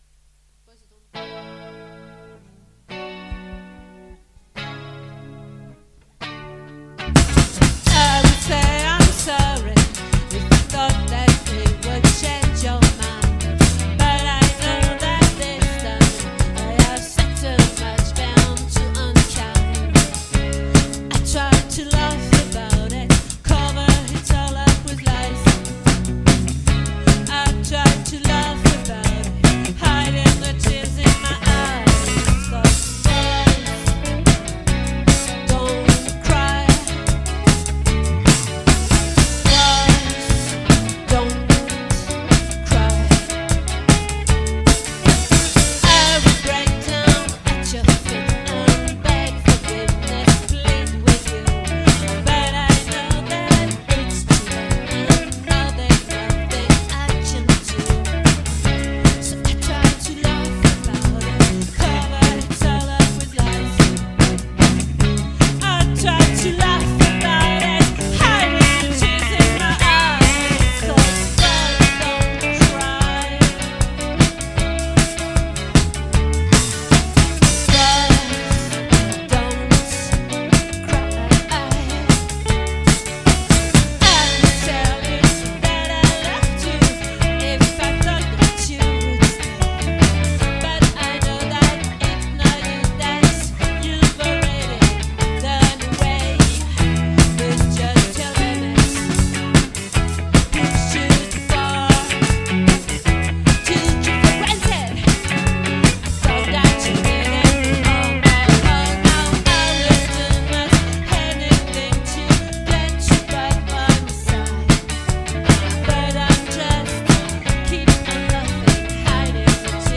🏠 Accueil Repetitions Records_2023_03_29_OLVRE